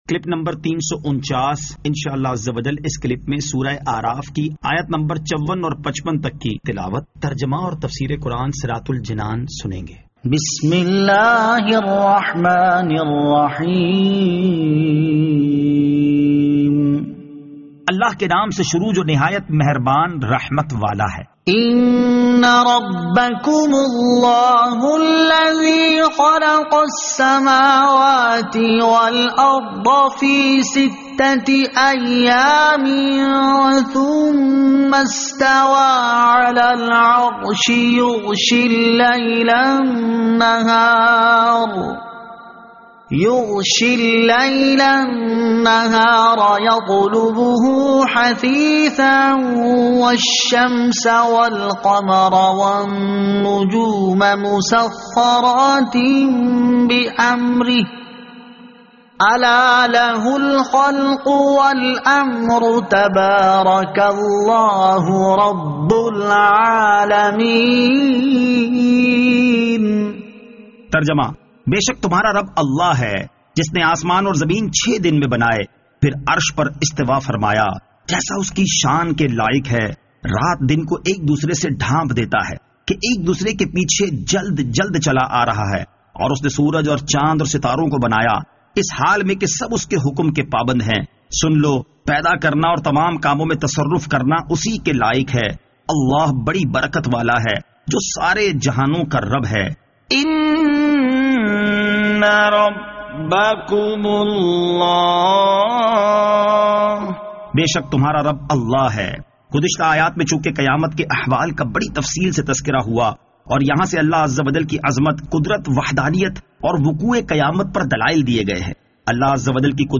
Surah Al-A'raf Ayat 54 To 55 Tilawat , Tarjama , Tafseer